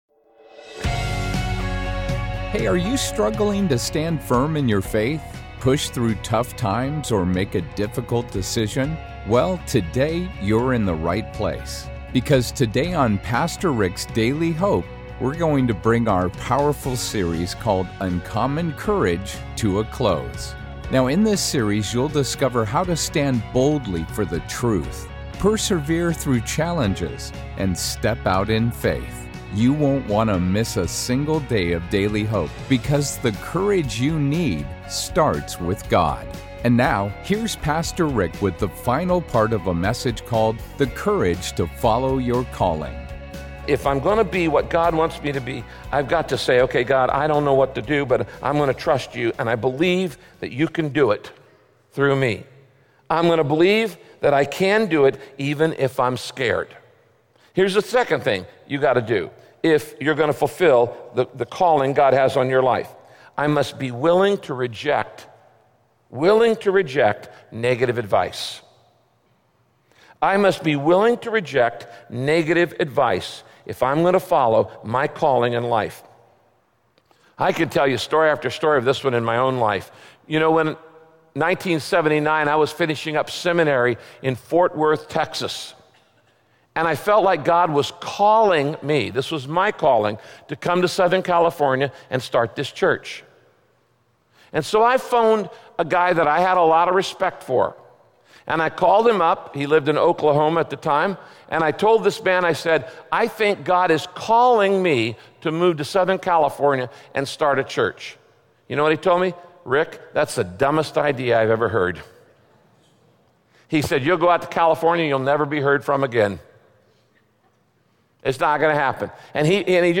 In this message, Pastor Rick explains why it…